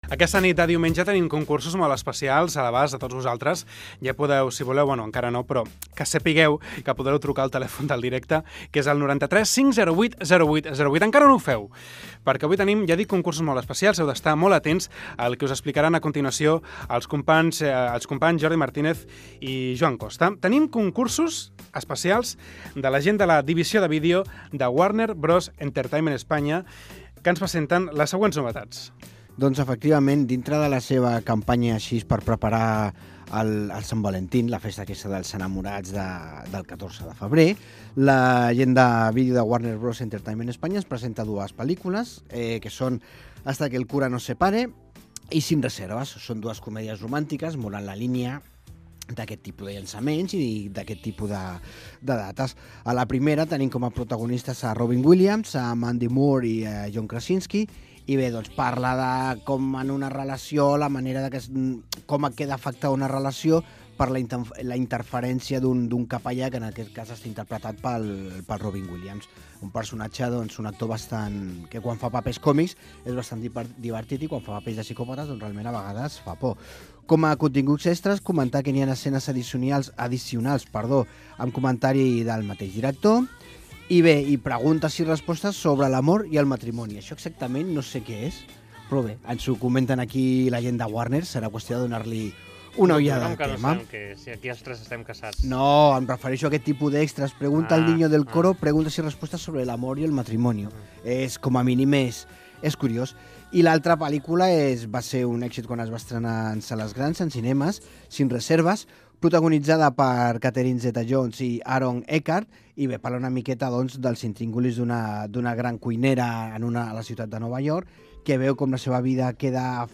Divulgació
Fragment extret de l'arxiu sonor de COM Ràdio